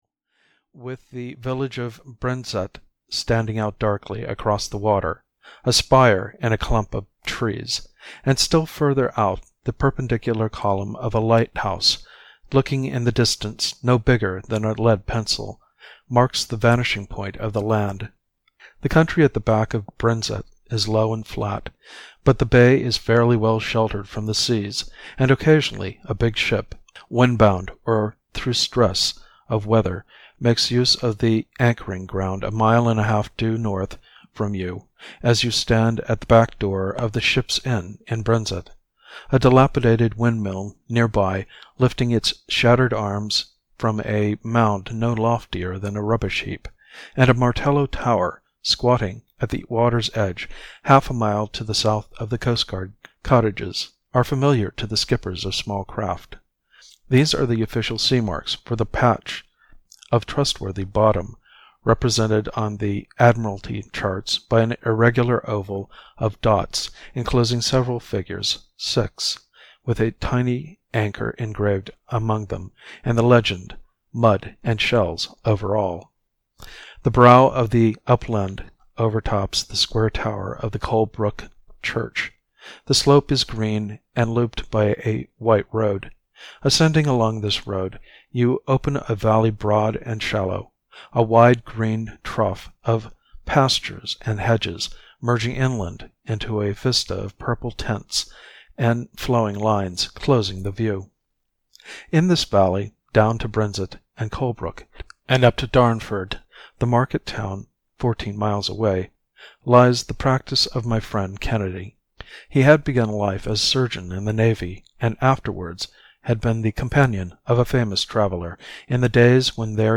Amy Foster (EN) audiokniha
Ukázka z knihy